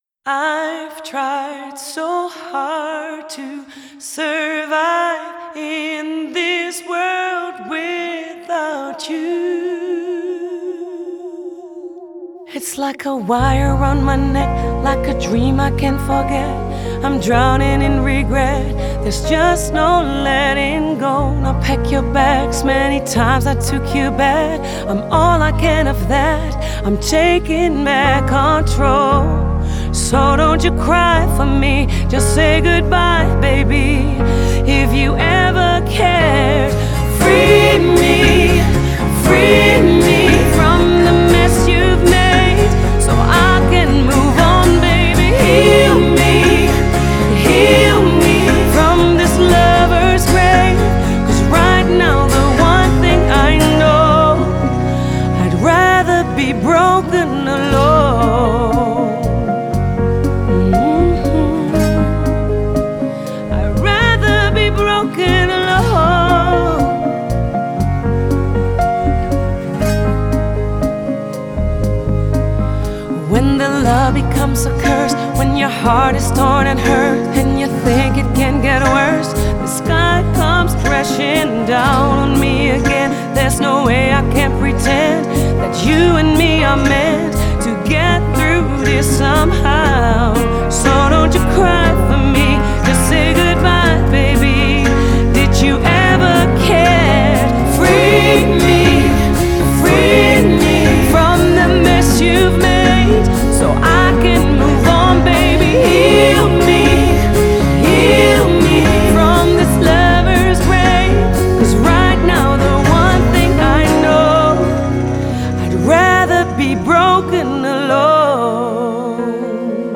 Genre: Pop / Soul / Jazz